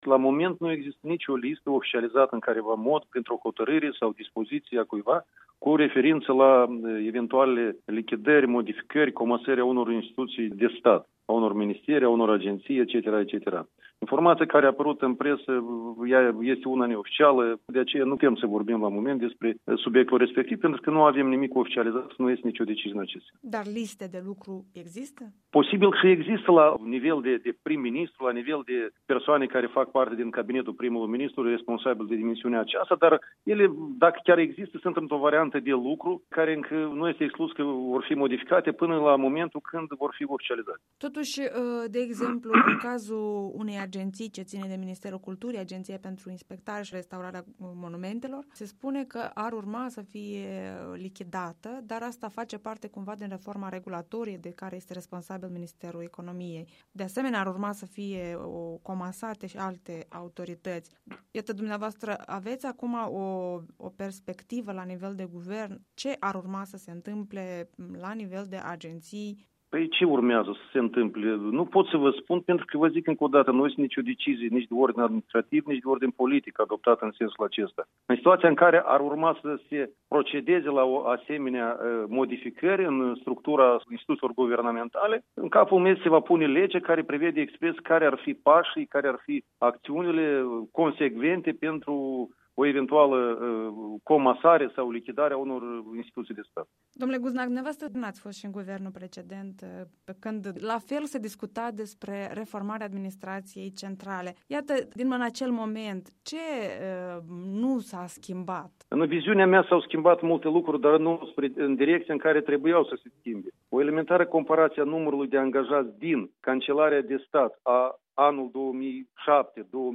Interviu cu secretarul general adjunct al guvernului de la Chișinău despre reforma administrativă programată pentru 2017